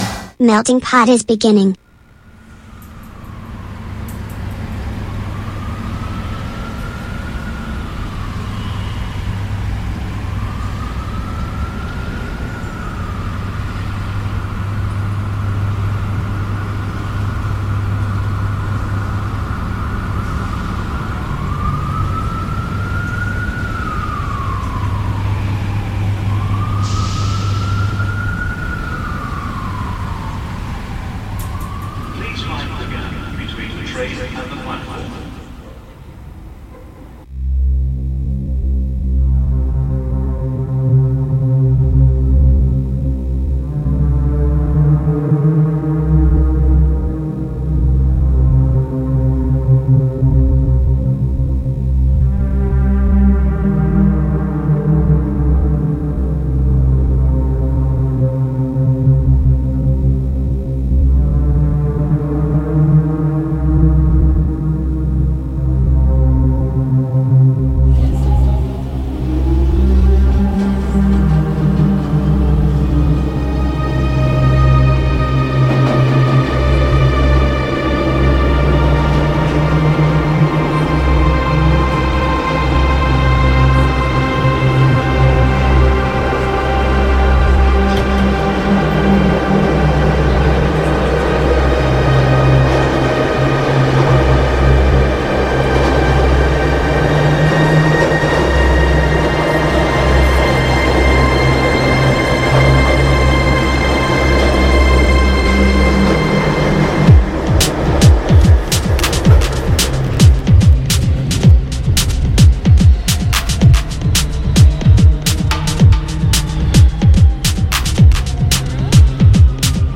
Un mercoledì di musica e attualità iniziato con la consueta ironia della clip tratta da Brian di Nazareth e proseguito tra riflessioni politiche e derive sonore.